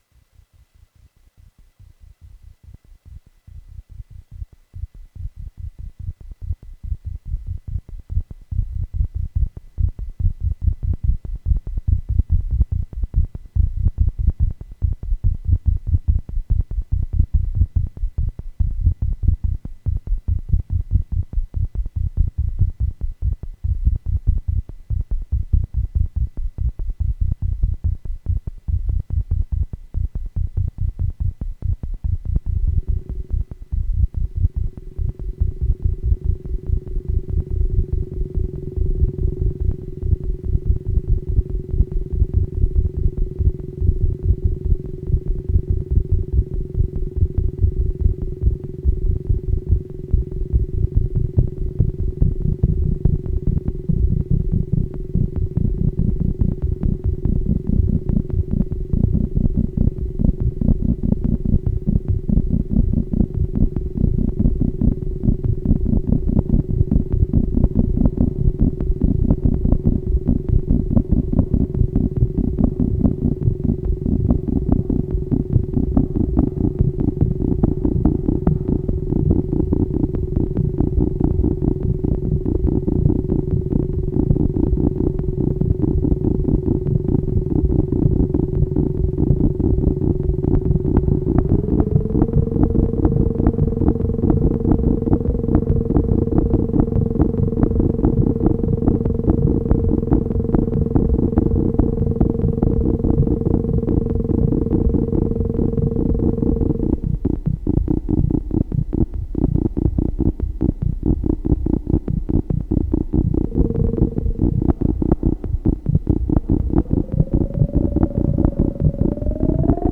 Pièce rythmique utilisant des modulations de sons par d'autres.
• Solo Système 35 (modulaire constitué des répliques Behringer du S35 Moog).
Rythmique très tribale aux sonorités très urbaine dans un style très Hard-techno.